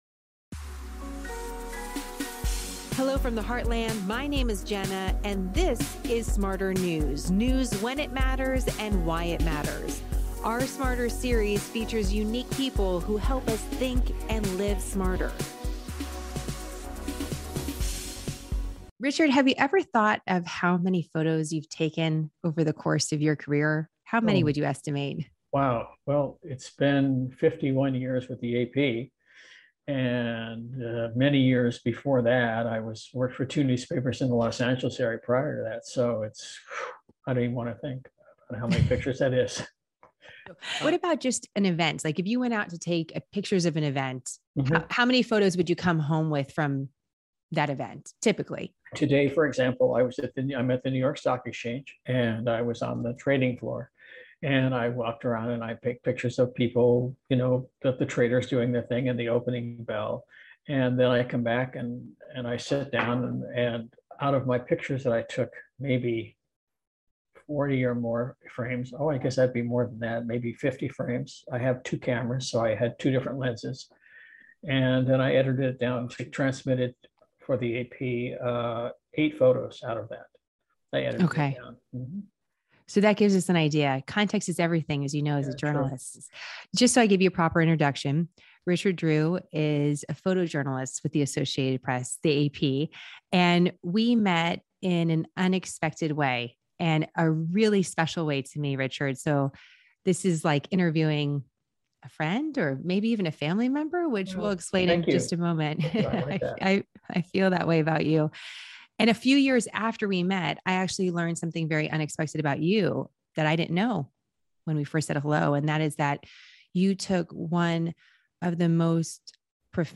Amongst the many historical photos he took that day, Richard captured a moment now known around the world as "The Falling Man." In this interview, Richard weaves us through the streets of New York City, sharing the moments before and after an unexpected assignment, and why he believes we shouldn't look away from this photo.